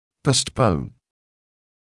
[ˌpəust’pəun][ˌпоуст’поун]откладывать; отсрочивать; переносить